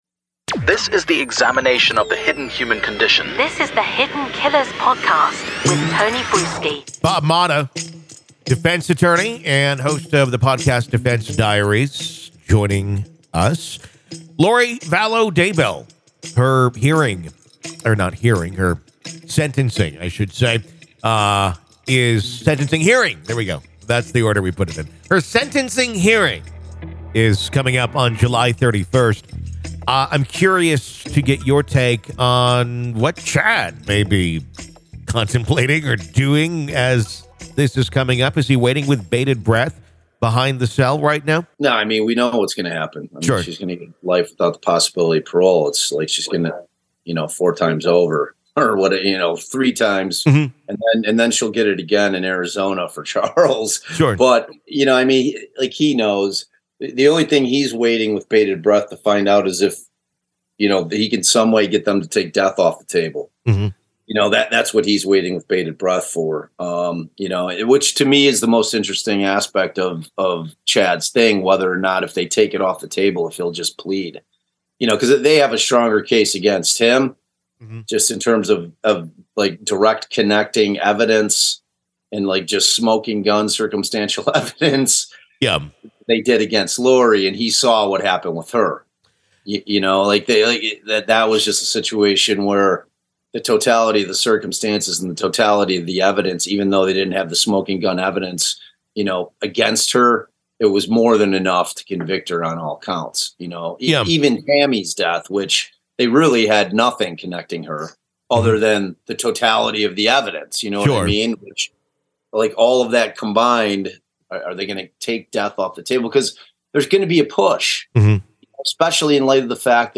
They also scrutinize the potential ramifications for Daybell if his team is indeed working to take the death penalty off the table. This compelling conversation sheds light on the fascinating yet complex world of legal defense strategy in high-profile cases.